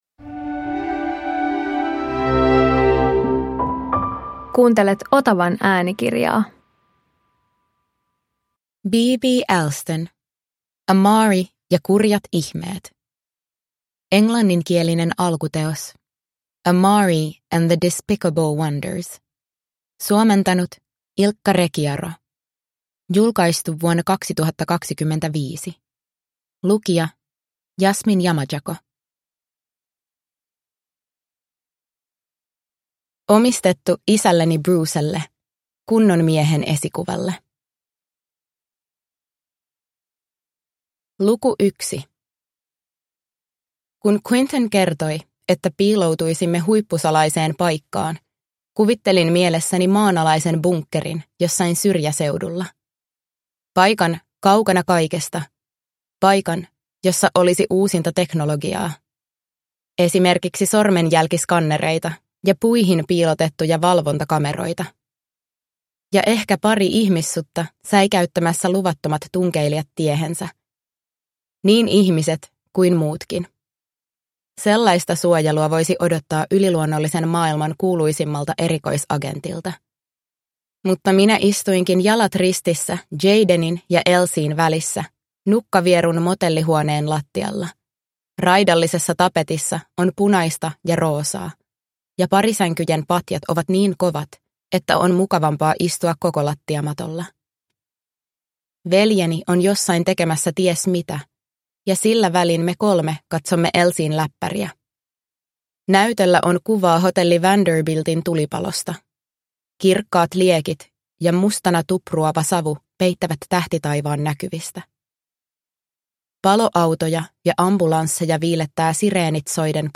Amari ja kurjat ihmeet – Ljudbok